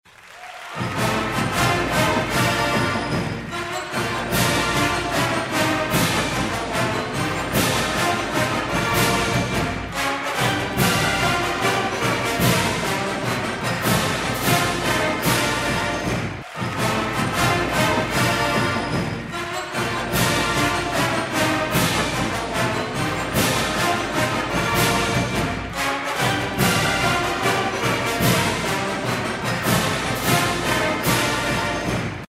Listen to the school song - Performed by the IU Marching Hundred in 1994
schoolsong_instrumental.mp3